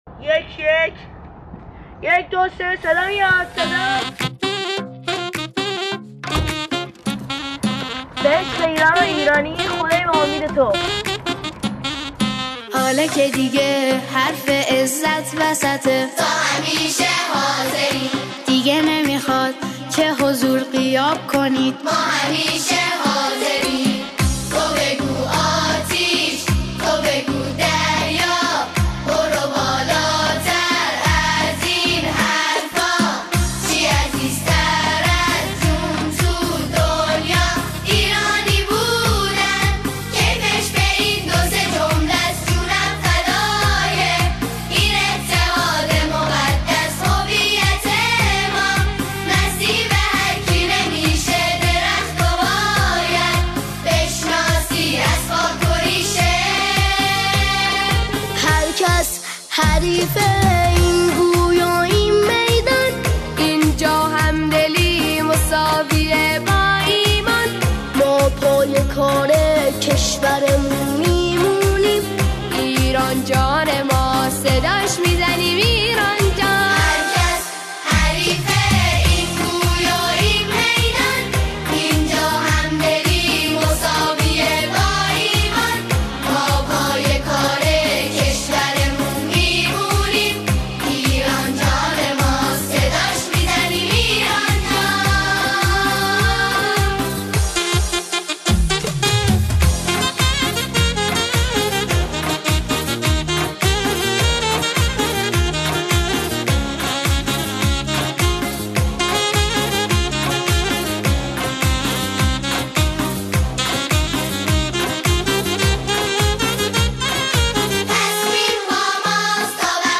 ژانر: سرود ، سرود انقلابی ، سرود مناسبتی